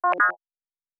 pgs/Assets/Audio/Sci-Fi Sounds/Interface/Data 15.wav at 7452e70b8c5ad2f7daae623e1a952eb18c9caab4